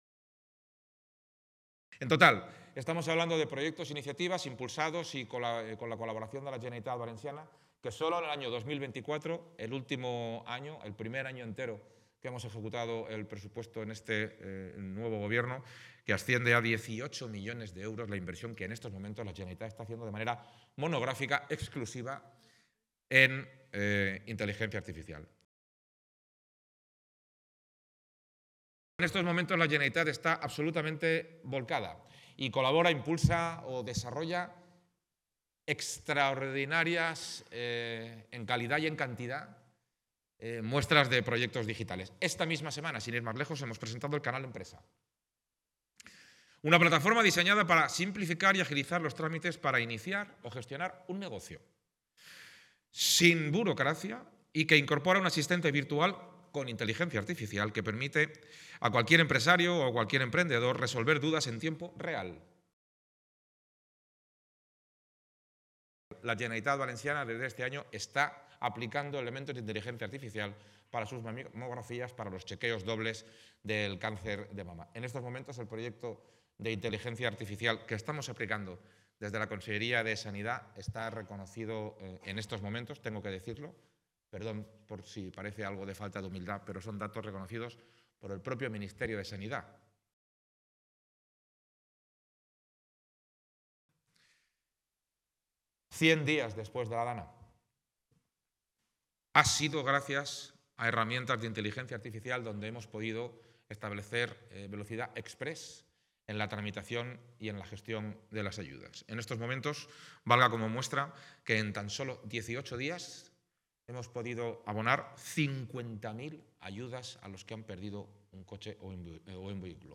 Así lo ha señalado en la inauguración del VII Congreso Internacional de Inteligencia Artificial organizado por El Independiente en Alicante donde ha defendido que los poderes públicos “deben ser motores” en el desarrollo de esta tecnología.